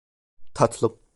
Ausgesprochen als (IPA) /tɑtɫɯm/